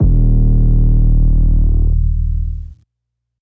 Adios 808.wav